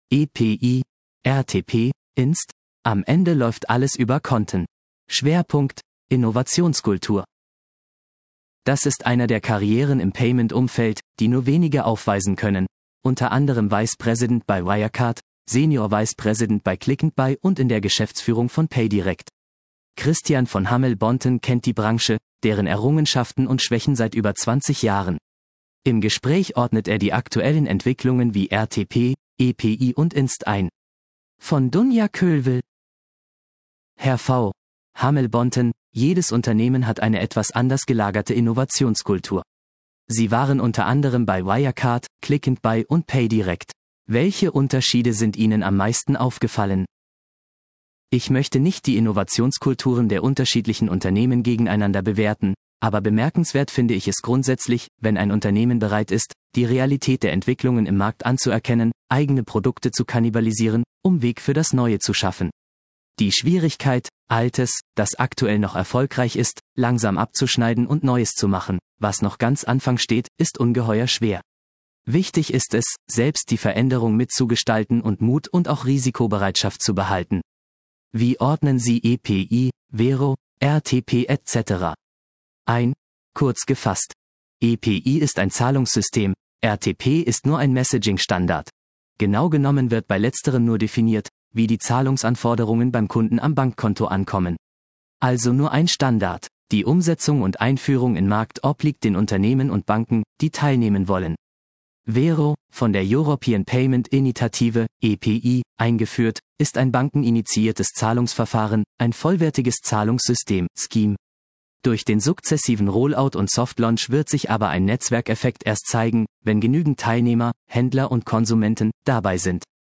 Im Interview ordnet er unter anderem RTP, EPI und Inst. ein.